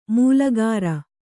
♪ mūlagāra